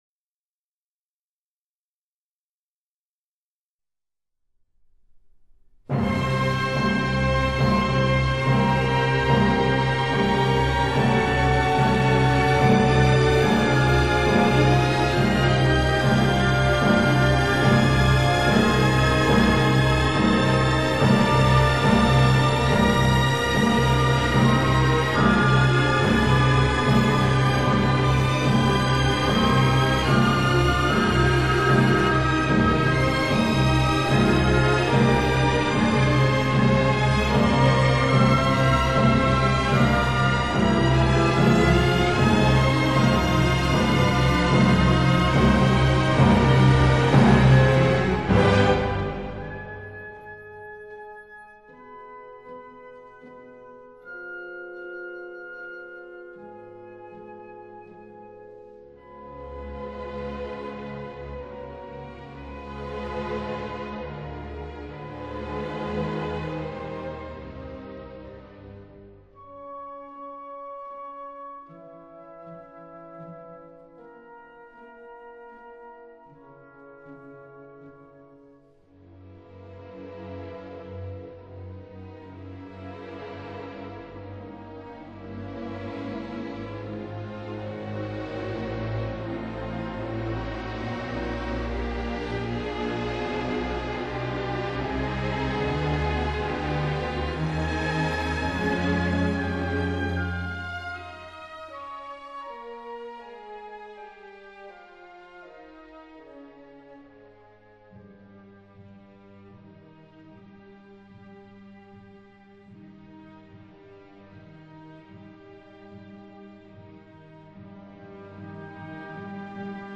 全曲共分四个乐章： 第一乐章 第一乐章：近似如歌的行板，快板，c小调，6/8拍子。序奏以强音开始，表现出恐怖紧张的气氛。
第二乐章 第二乐章：持续的行板，E大调，3/4拍子，带有类似第一乐章的寂寥阴暗的悲剧色彩。
第三乐章 第三乐章：温雅而略快的快板，降A大调，2/4拍子。
第四乐章 第四乐章：不快而灿烂的快板，C大调，4/4拍子。